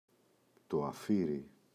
αφίρι, το [a’firi]